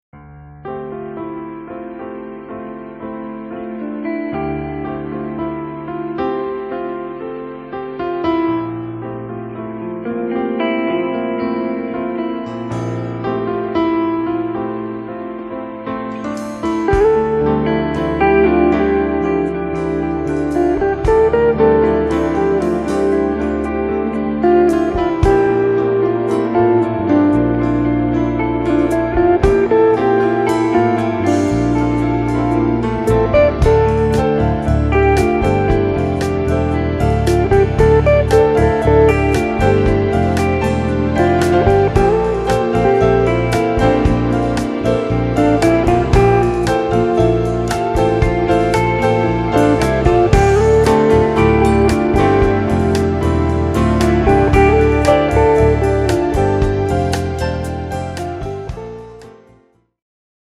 Experimental instrumental music